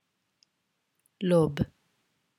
The listening will help you with the pronunciations.